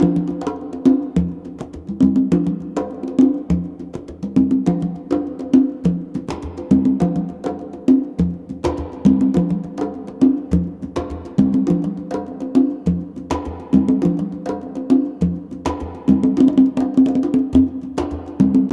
CONGBEAT11-L.wav